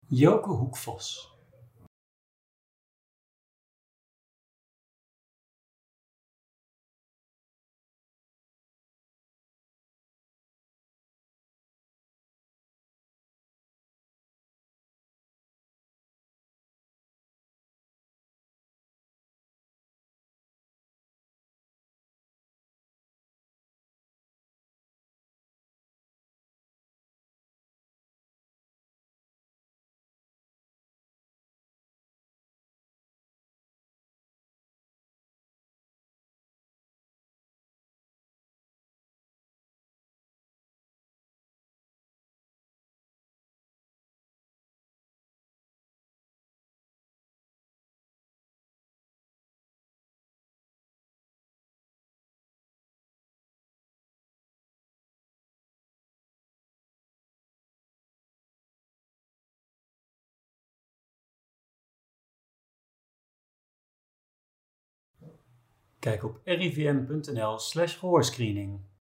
De gezinsbegeleider